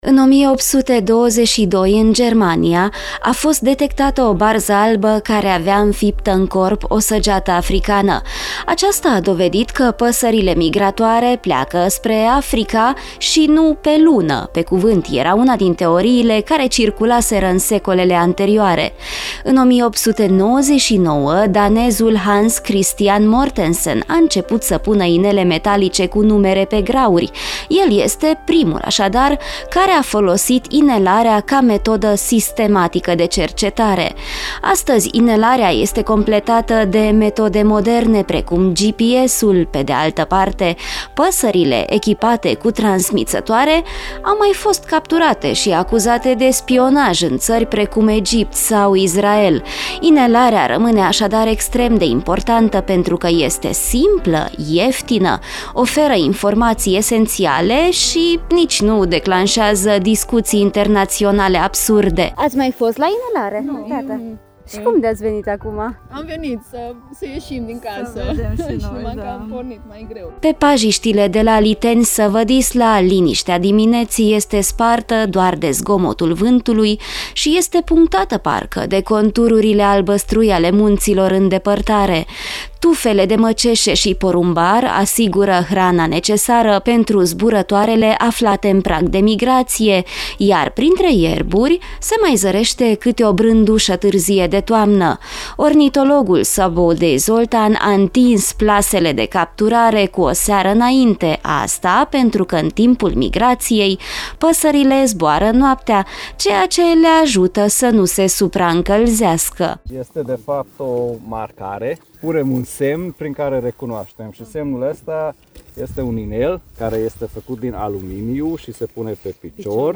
Organizaţia pentru Protecţia Mediului şi Combaterea Braconajului, alături de Grupul Milvus, i-a invitat recent pe clujeni la un eveniment educativ și științific pe pajiștile de la Liteni-Săvădisla. Participanții au avut ocazia să înveţe despre rolul inelării în cercetare, să observe păsările de aproape și să afle despre pericolele la care acestea sunt expuse.
Lecția din teren
Pe pajiștile de la Liteni-Săvădisla, liniștea dimineții este spartă doar de zgomotul vântului şi este punctată parcă de contururile albăstrui ale munților în depărtare.